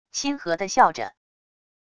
亲和的笑着wav音频